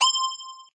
fill_cube.ogg